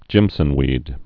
(jĭmsən-wēd)